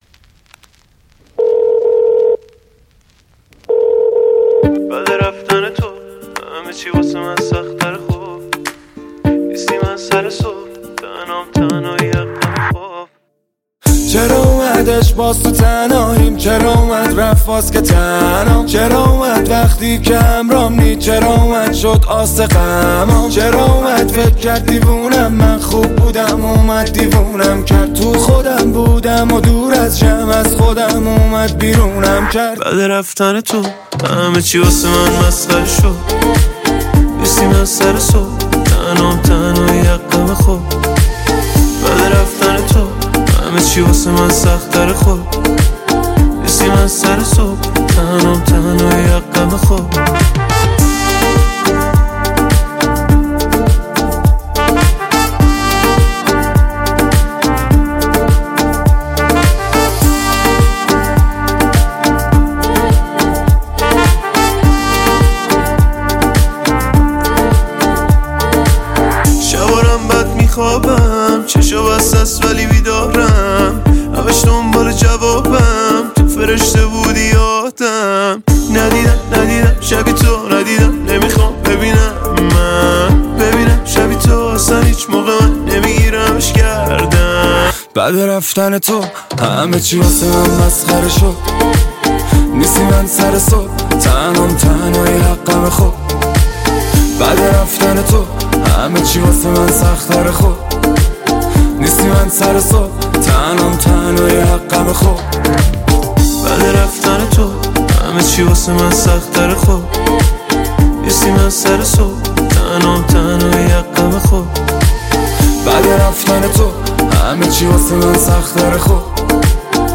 در سبک پاپ